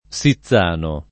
Sizzano [ S i ZZ# no ]